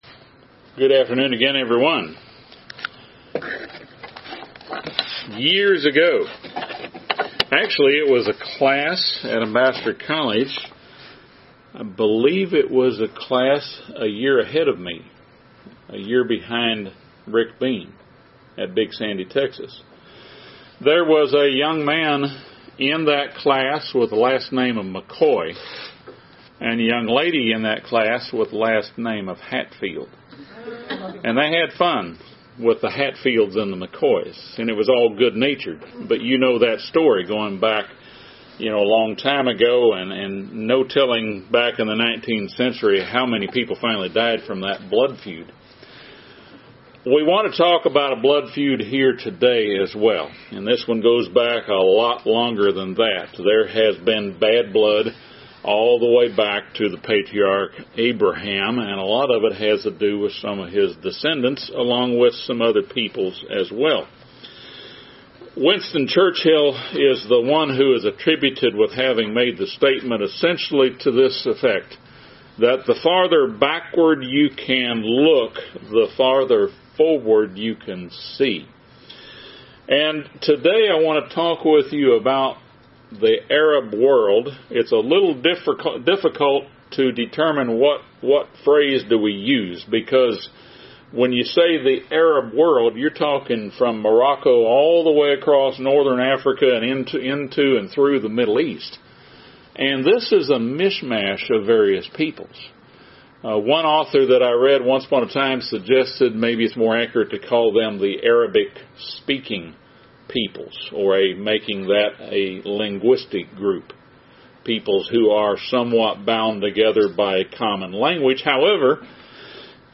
This sermon discusses the historical roots of the conflict between Islam and the Western World.